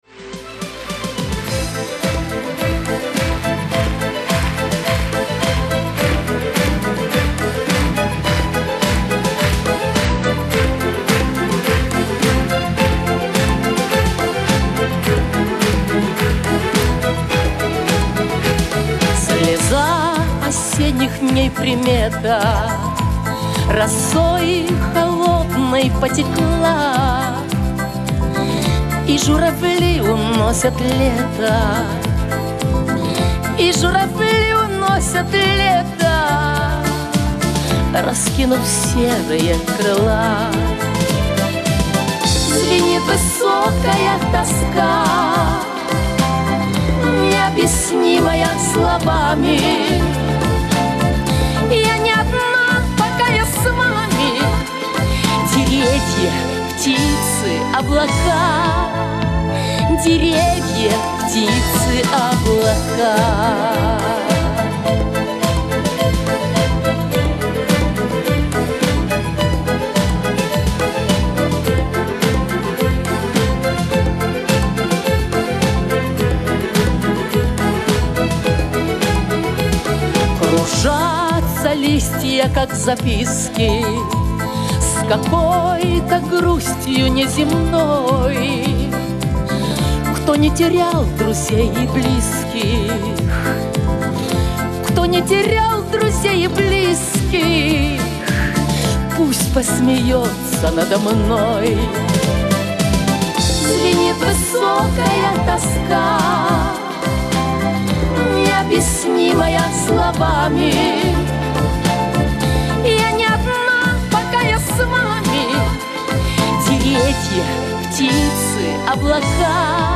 Режим: Mono